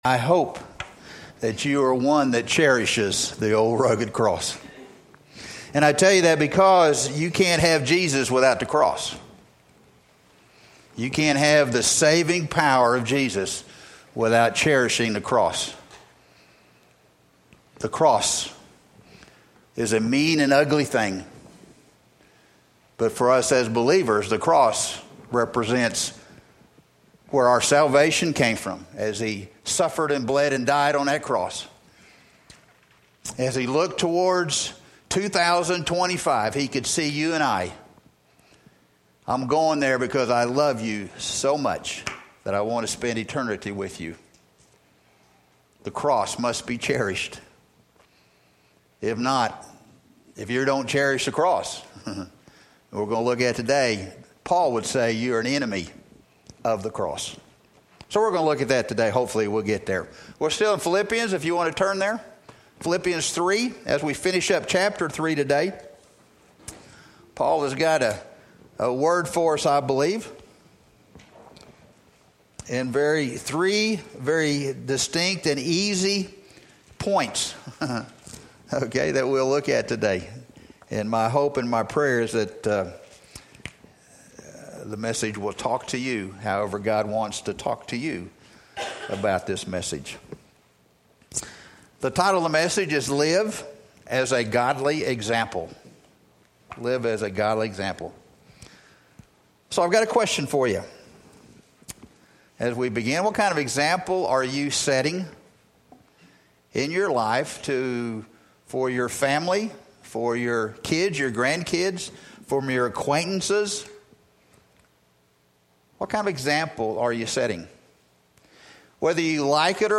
Sermons by Arapaho First Baptist Church